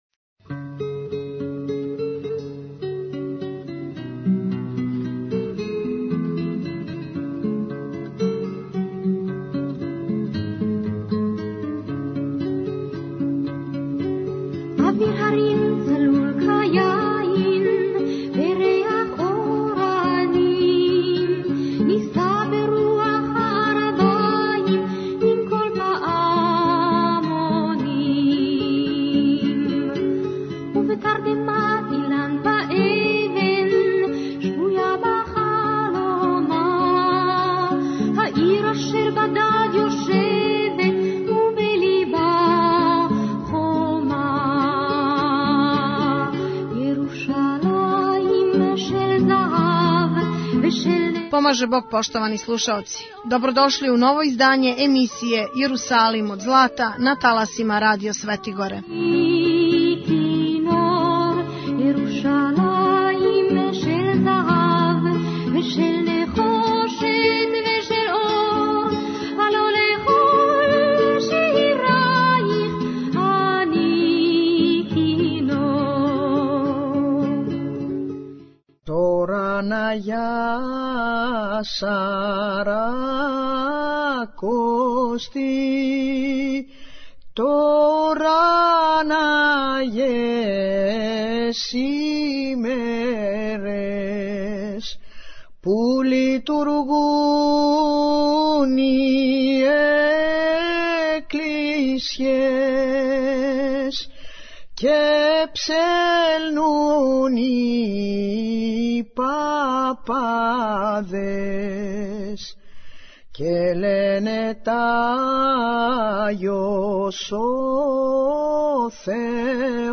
у другом дијелу емисије чућемо и Његово блаженство патријарха јерусалимског Теофила Трећег о појави Благодатног огња који се догађа искључиво православном патријарху.